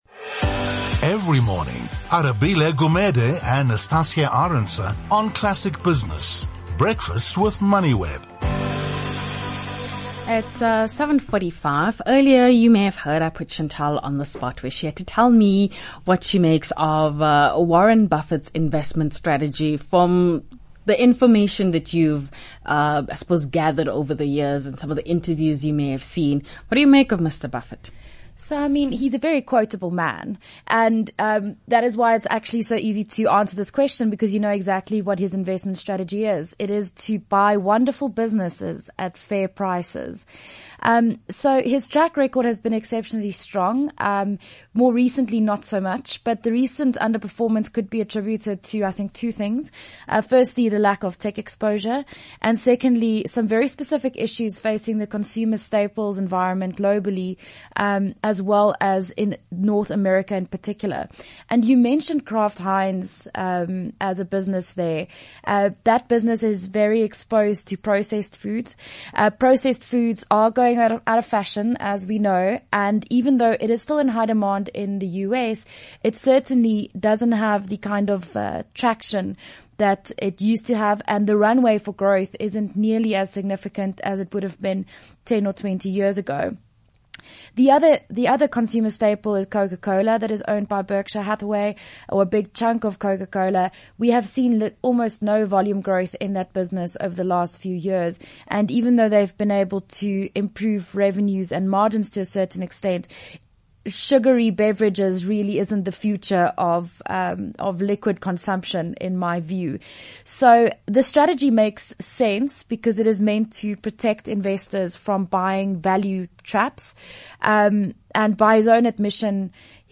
The Classic Business Breakfast is the only dedicated morning business show in South Africa.
It is also streamed live on Moneyweb.